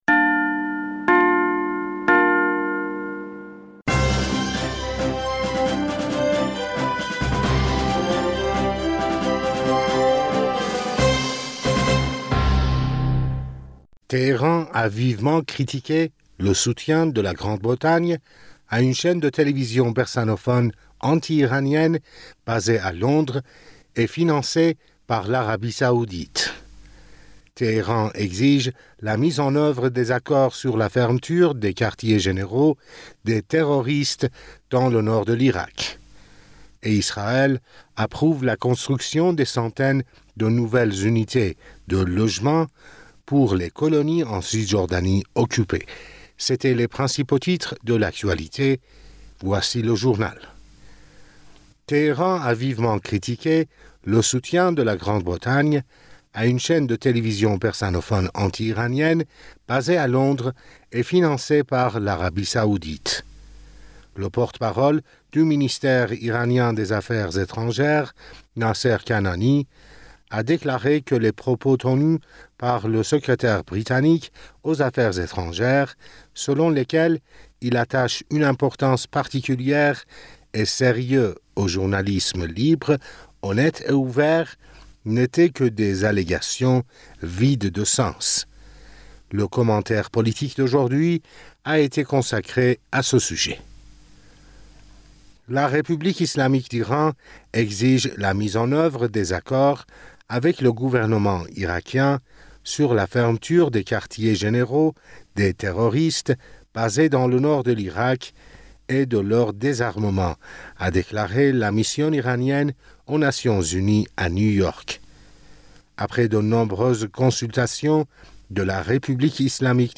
Bulletin d'information du 24 Novembre